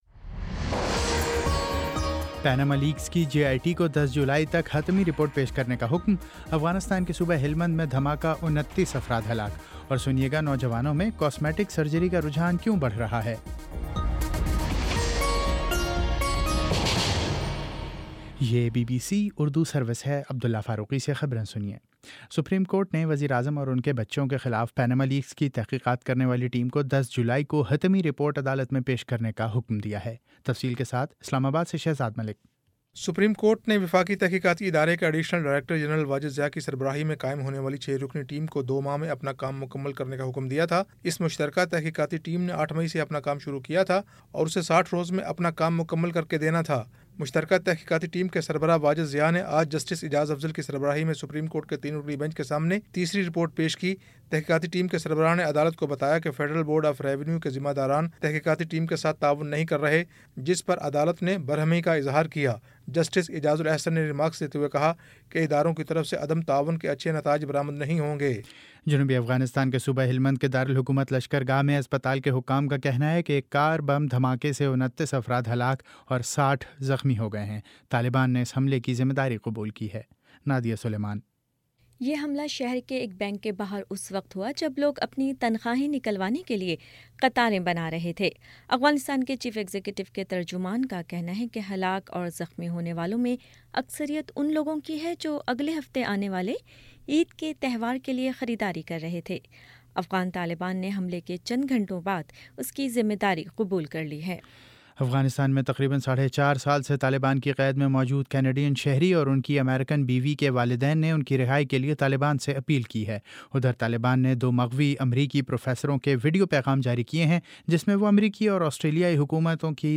جون 22 : شام سات بجے کا نیوز بُلیٹن